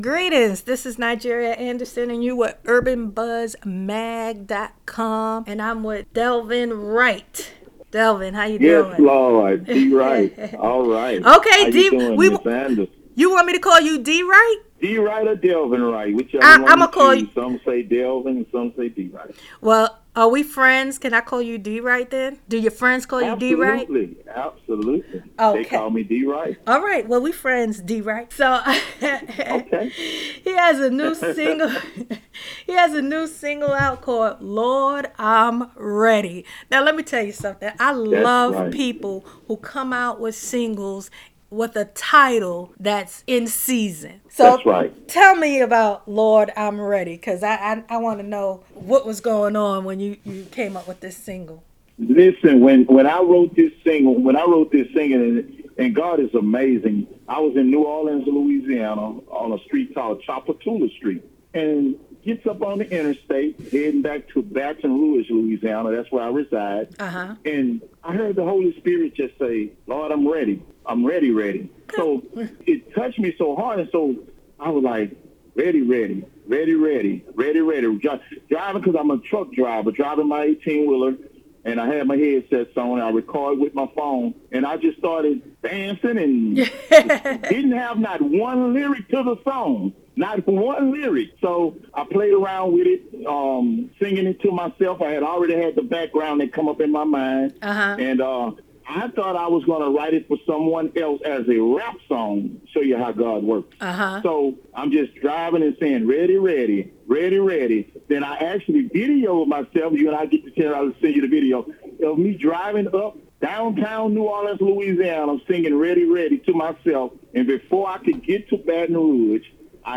Join in on our conversation